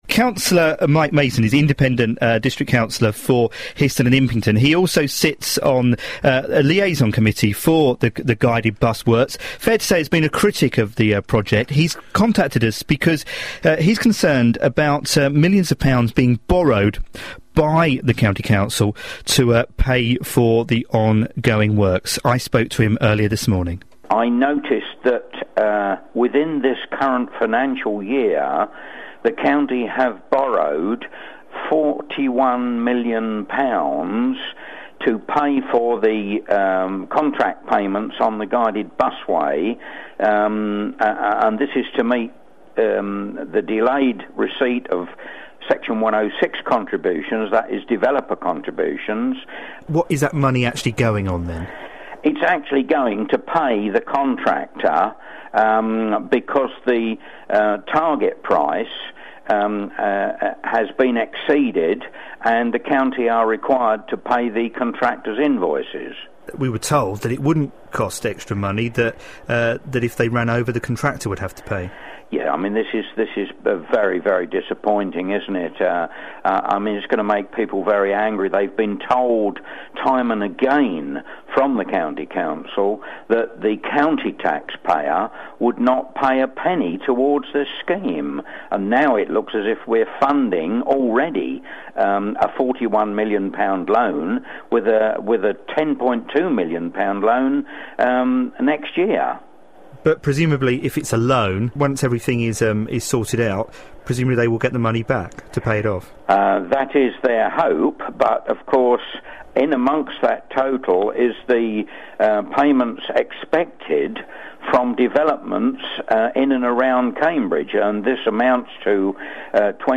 interviewed on Radio Cambs